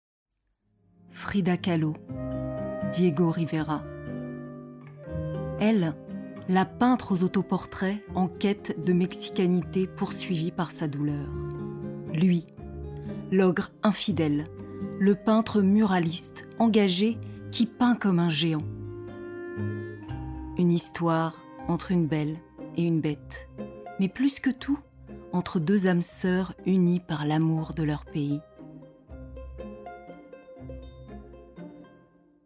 Voix off
35 - 50 ans - Mezzo-soprano